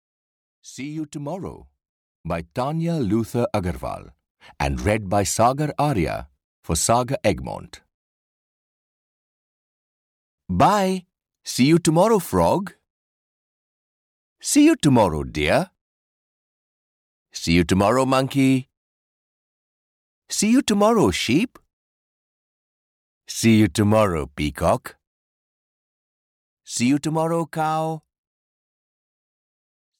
See You Tomorrow (EN) audiokniha
Ukázka z knihy